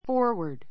forward 中 A2 fɔ́ː r wə r d ふォ ーワ ド 副詞 前へ, 前方へ go [step] forward go [step] forward 前進する[一歩前へ出る] swing forward and backward 反対語 swing forward and backward 前後に揺 ゆ れる We are moving the plan forward.